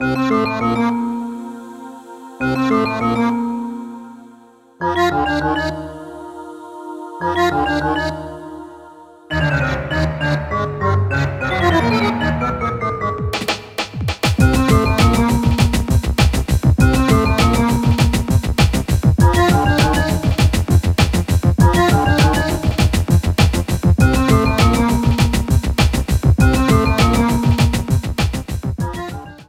Ripped from the game
trimmed to 29.5 seconds and faded out the last two seconds